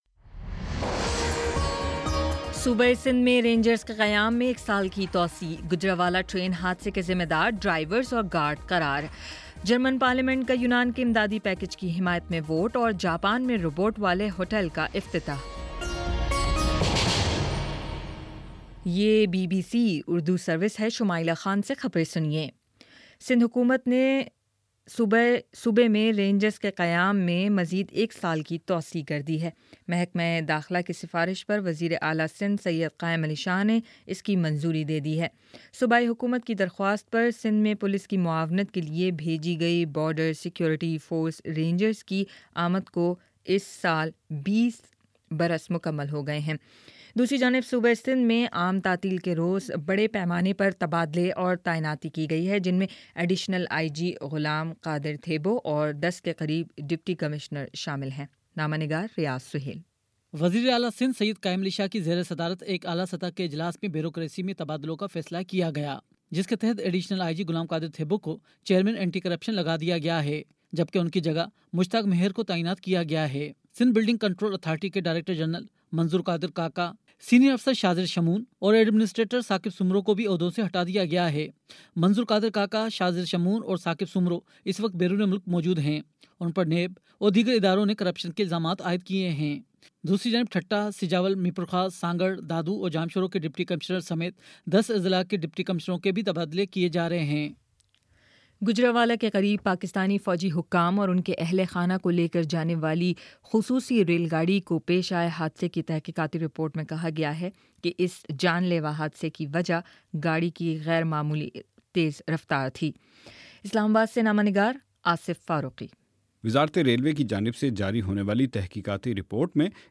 جولائی 17: شام سات بجے کا نیوز بُلیٹن